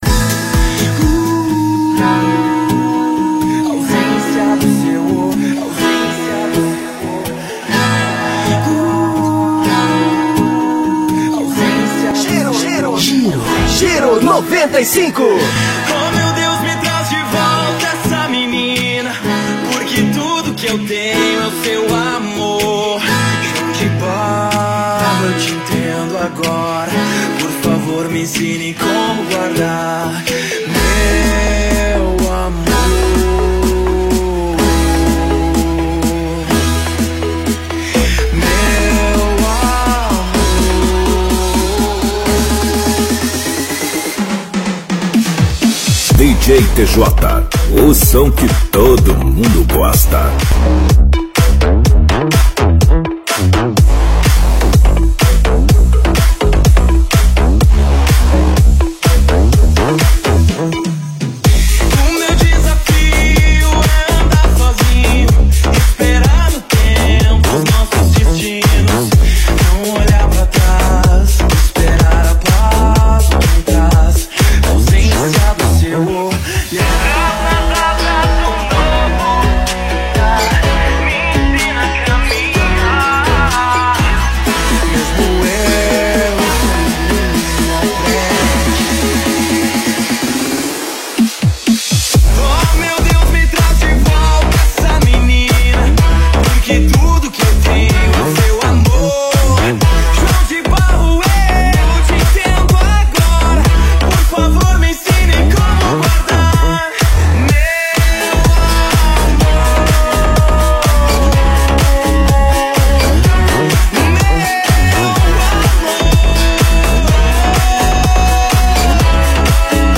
house music e suas vertentes